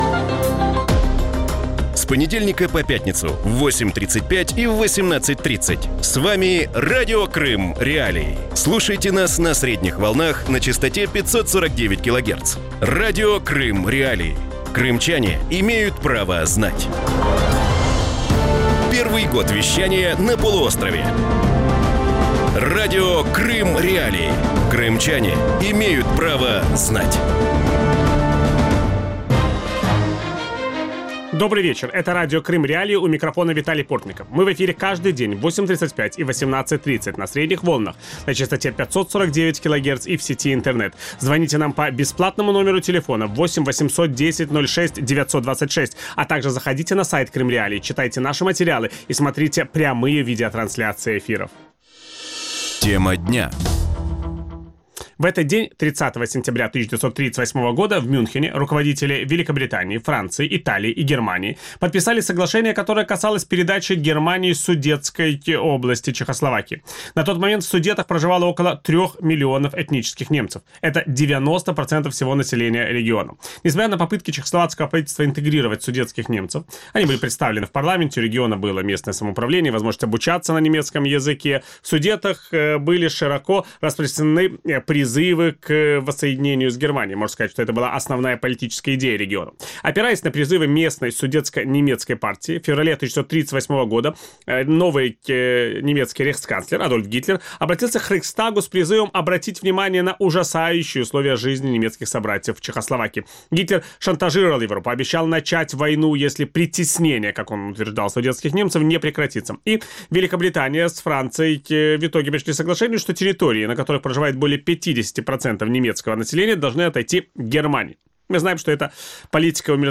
У вечірньому ефірі Радіо Крим.Реалії обговорюють історичні паралелі між анексією Криму в 2014 році і передачею Німеччині Судетської області Чехословаччини у 1938 році. Чи працює політика умиротворення агресора, чи вивчив Захід історичний урок і що історія говорить про анексовані території?
Ведучий: Віталій Портников.